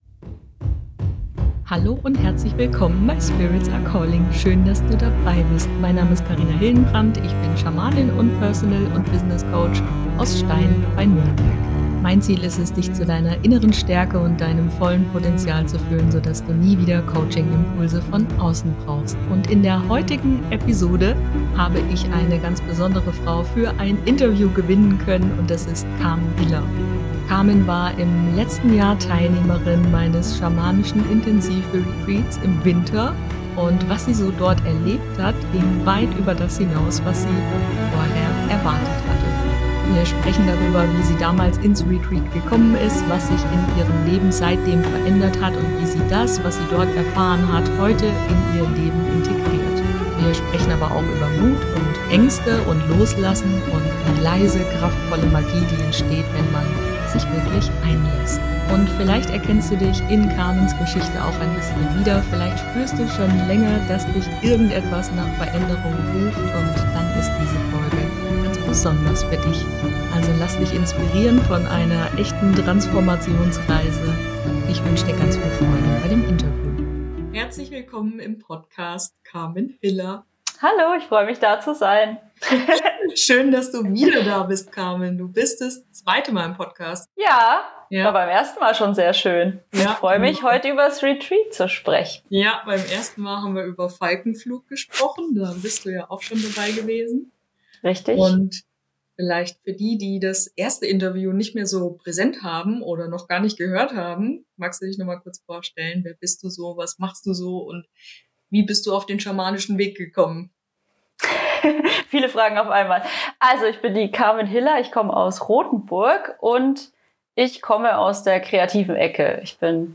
Wir sprechen darüber, was es bedeutet, wirklich loszulassen. Über die Angst vor Veränderung, über Vertrauen und darüber, wie sich innere Stärke manchmal ganz leise zeigt.